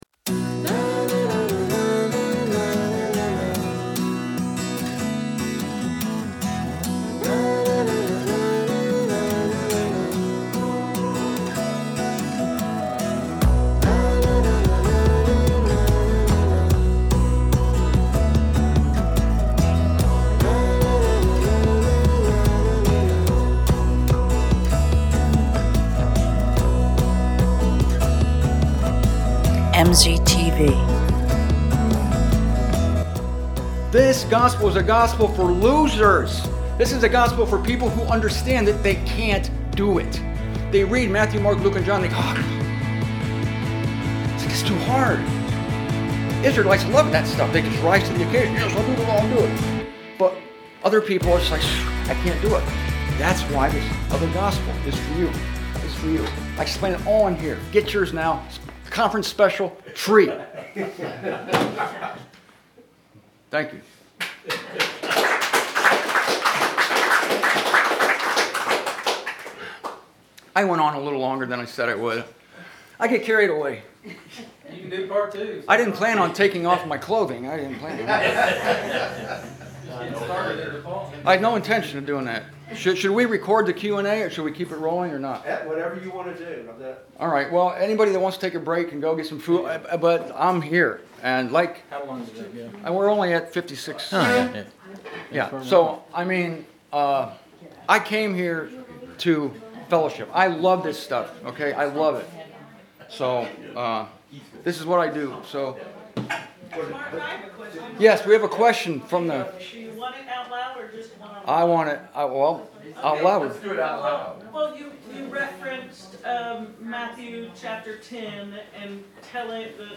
The questions came fast and furious at the Friday night meeting in Evansville. And so did the answers.